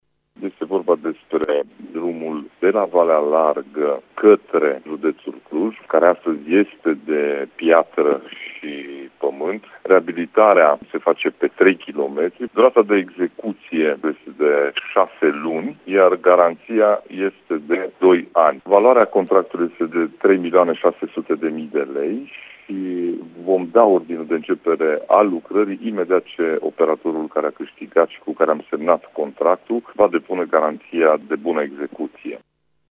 Președintele CJ Mureș, Ciprian Dobre: